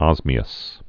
(ŏzmē-əs)